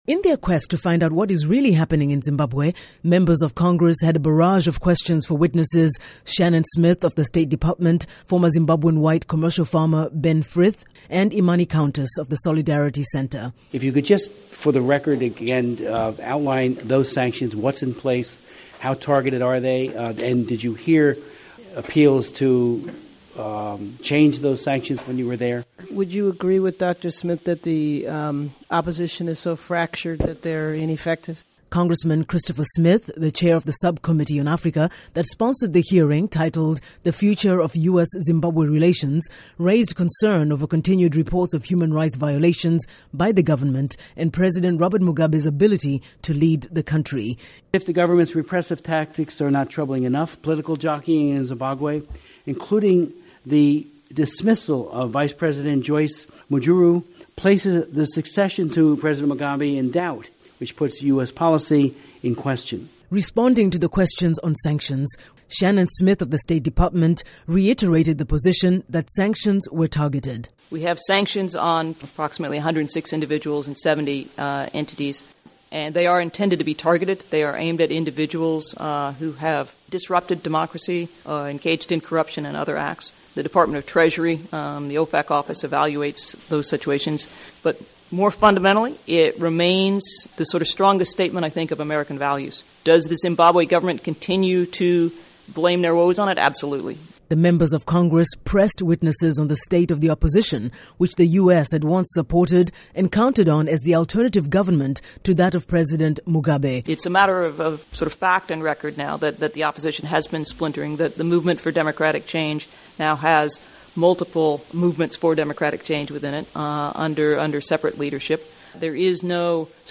Report on U.S. - Zimbabwe Relations